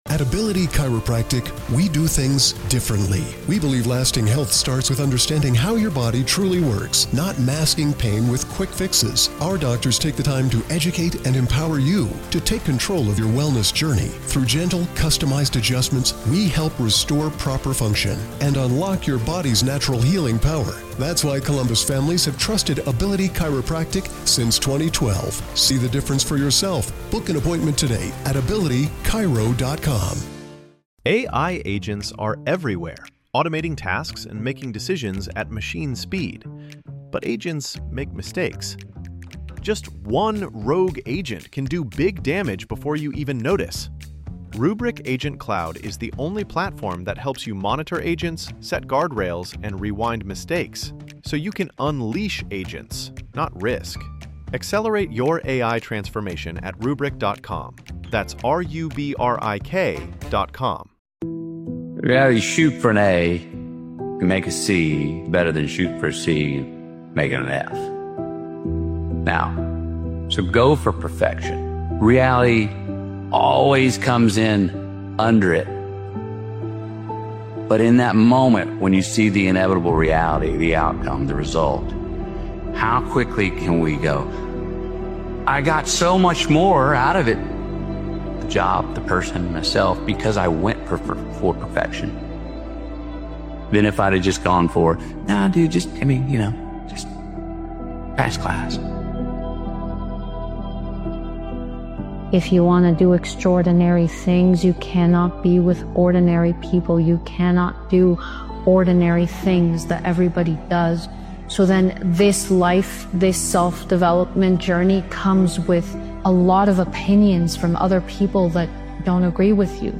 This powerful motivational speeches compilation reminds you that progress isn’t about removing the struggle—it’s about elevating your mindset until the struggle no longer breaks you. Every challenge, every repetition, every disciplined day sharpens your edge.